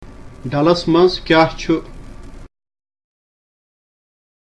A conversation with a na:vivo:l (a boatman) about sight-seeing on and around Dal Lake.